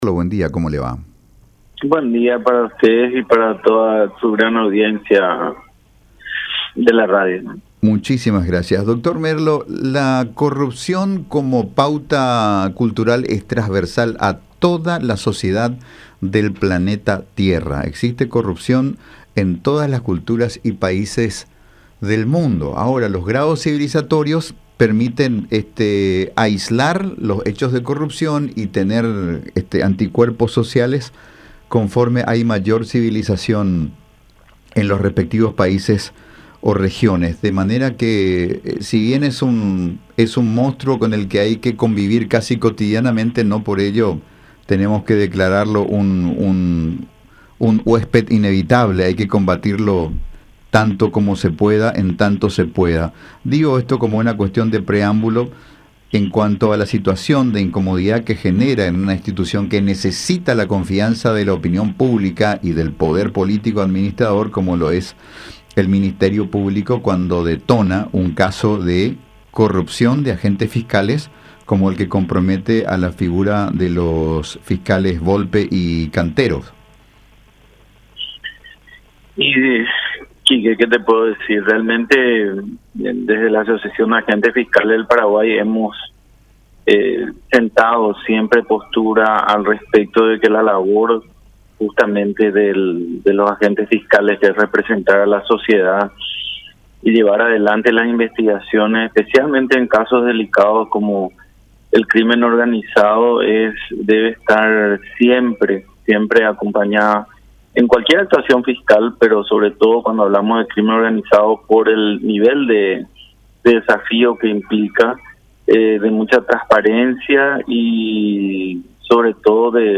en diálogo con La Unión